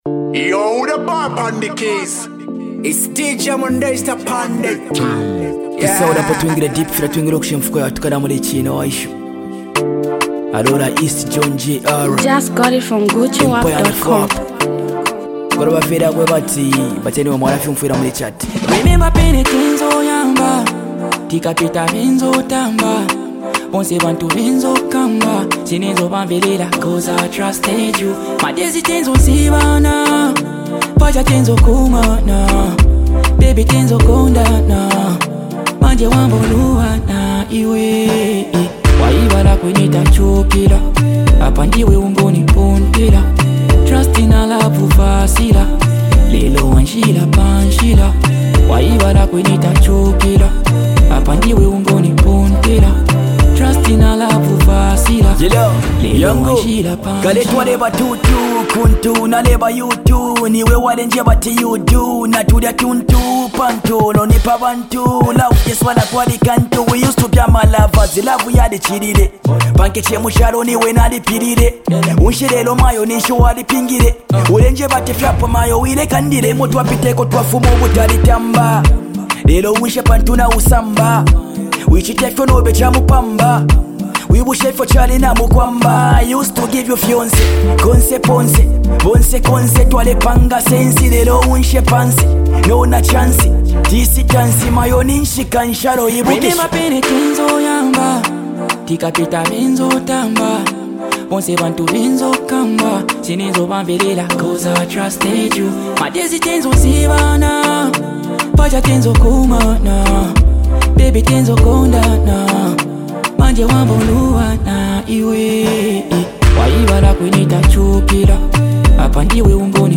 versatile skills as a singer, rapper